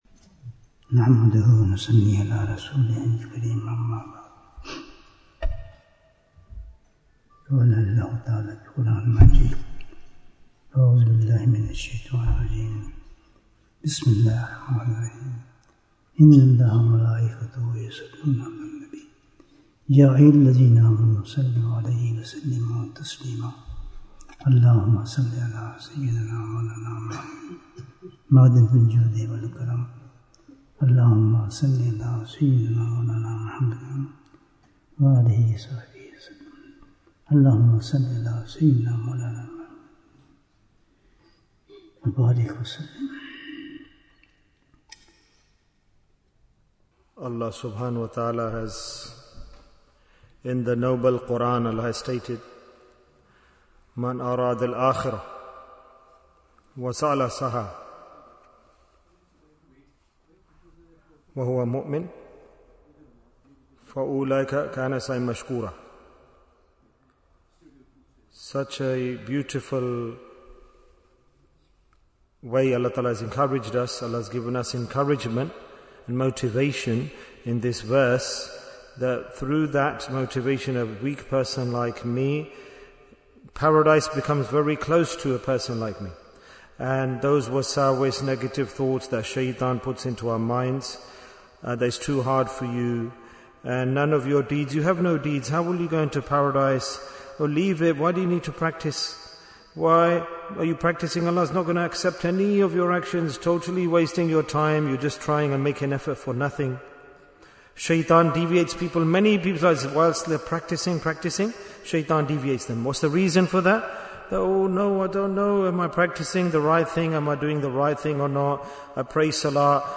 Bayan on Eid-ul-Fitr Bayan, 45 minutes20th March, 2026